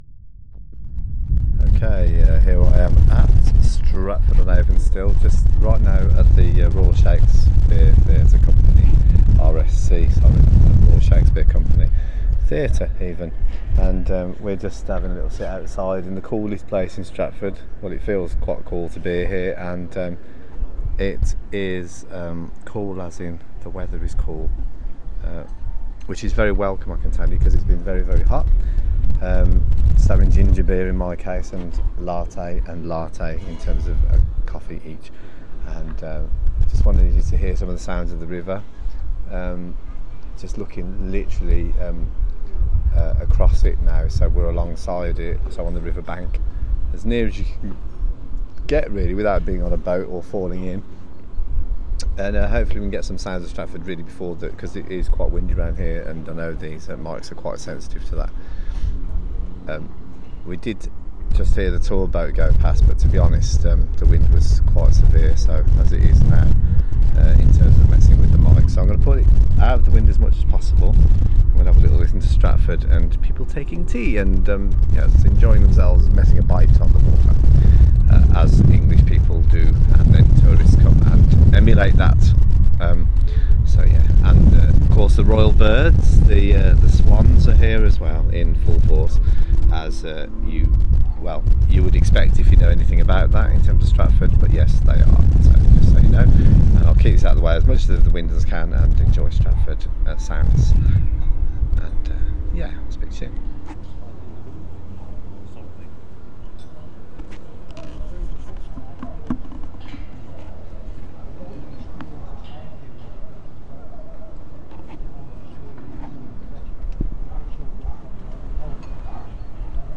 By the river alongside the rsc theatre